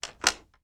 Lock Deadbolt Unlock Sound
household